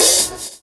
Closed Hats
Wu-RZA-Hat 45.wav